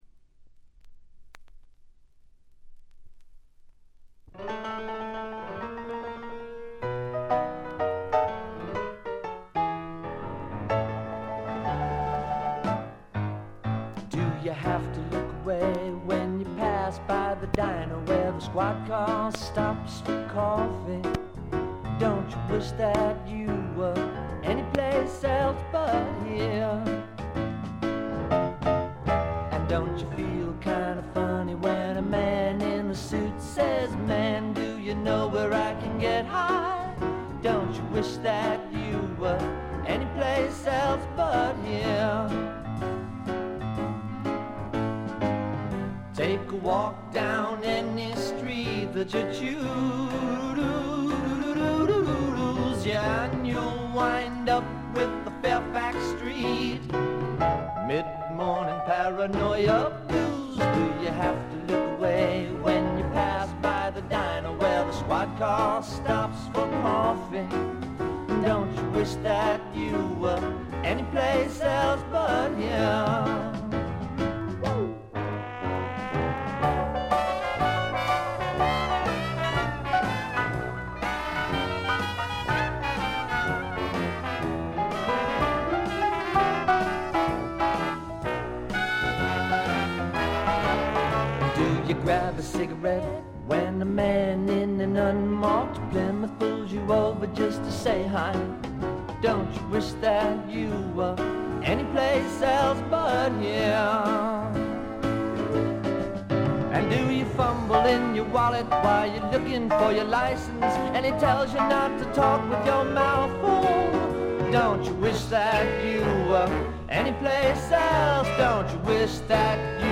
A5冒頭の凹部で5回大きなプツ音が出ます。これ以外はわずかなノイズ感のみで良好に鑑賞できると思います。
試聴曲は現品からの取り込み音源です。
Recorded in Hollywood, California.